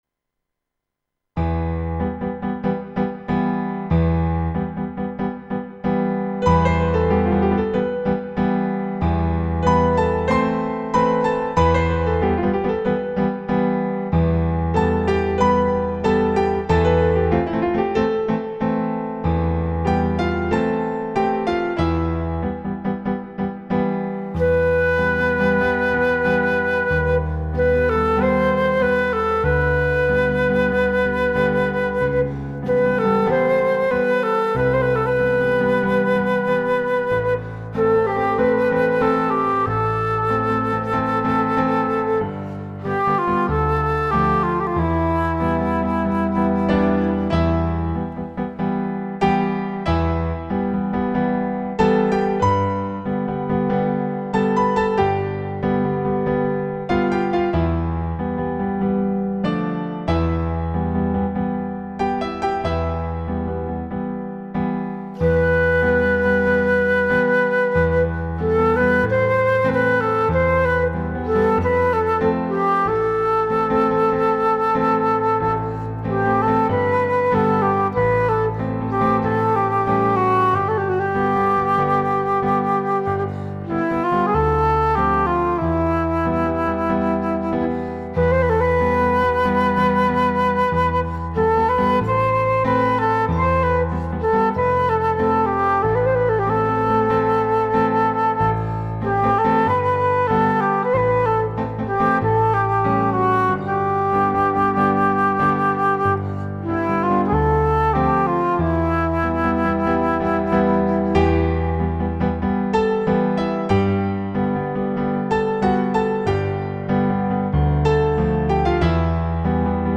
۲. فایل صوتی MP3 اجرای مرجع
• اجرای دقیق و وفادار به نت‌های تنظیم‌شده
با ملودی لطیف، فضای عاشقانه و عمق احساسی بالا
ایرانی